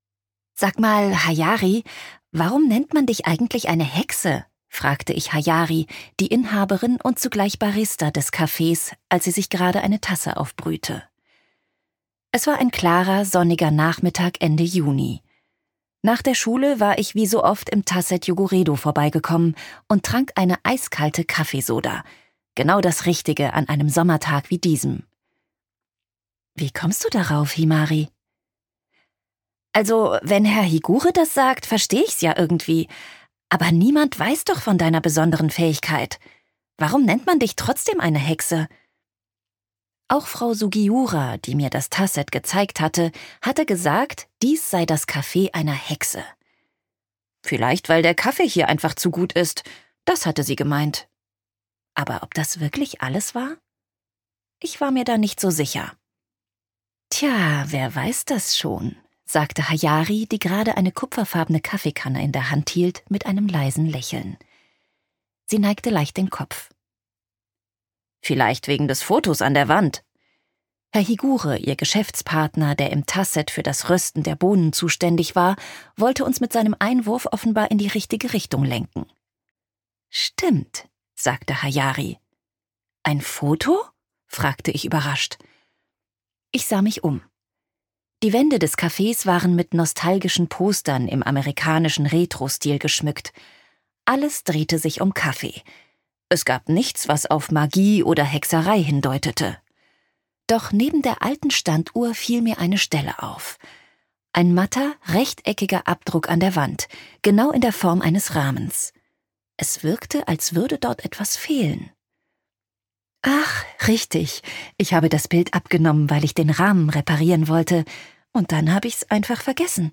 Ein tröstliches Wohlfühlhörbuch für alle, die heilende Literatur wie Frau Komachi empfiehlt ein Buch lieben.
Gekürzt Autorisierte, d.h. von Autor:innen und / oder Verlagen freigegebene, bearbeitete Fassung.